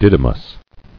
[did·y·mous]